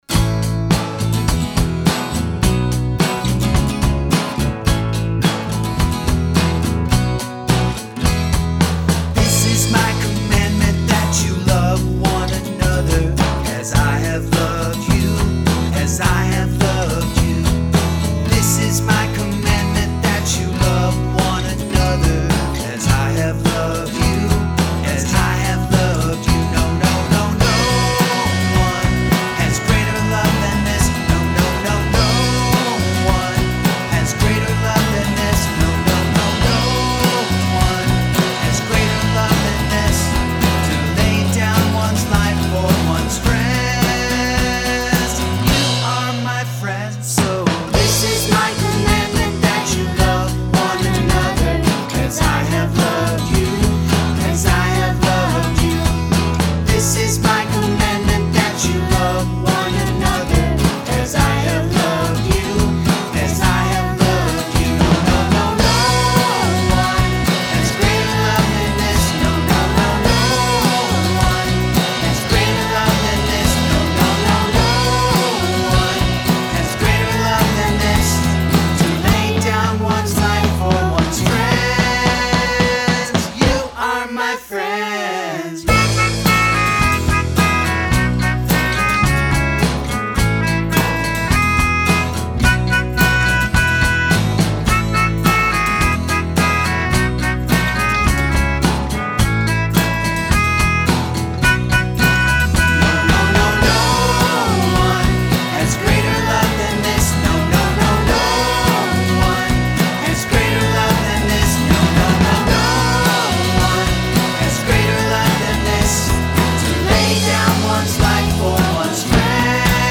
Scripture song
camp song